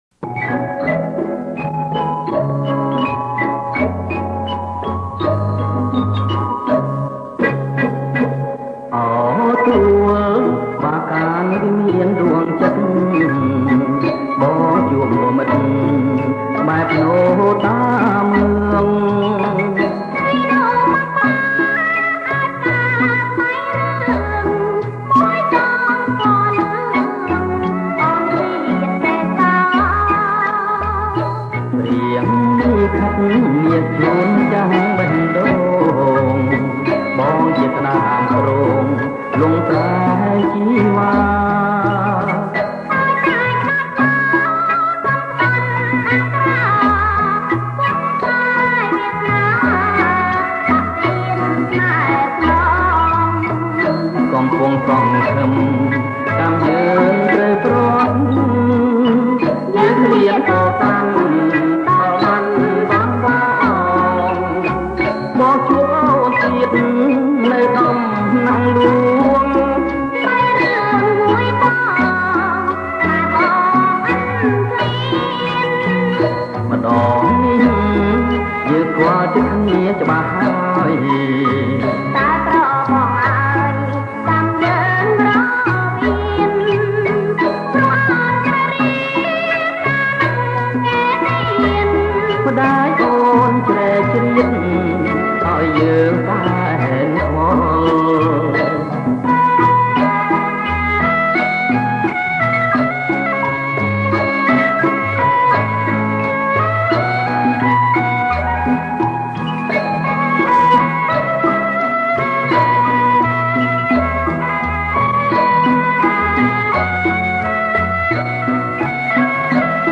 • ប្រគំជាចង្វាក់ Bolero